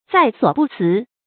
在所不辞 zài suǒ bù cí 成语解释 辞：推辞。